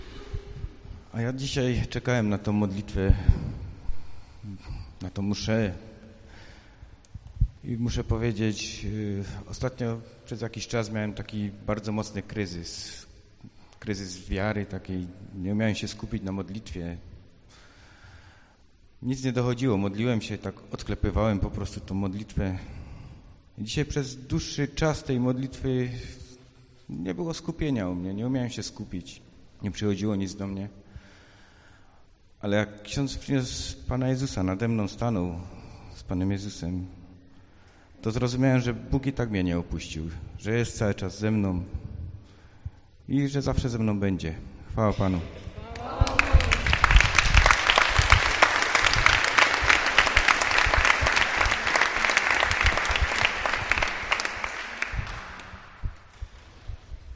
Po Mszy Św. i modlitwie zwykle uczestnicy podchodzą do mikrofonu i dzielą się świadectwem.